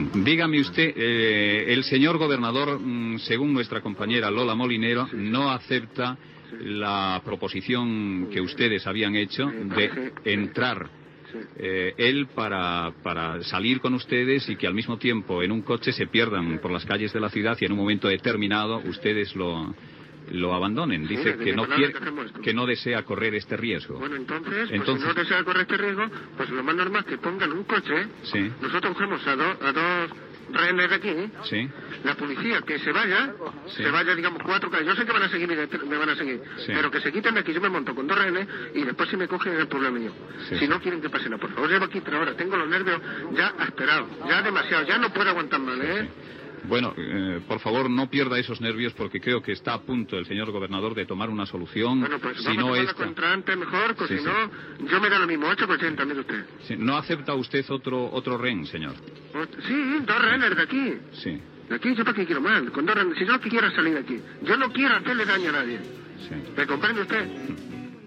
Conversa amb un segrestador d'un atracament a una sucursal del Banco Hispano Americano de Barcelona
Info-entreteniment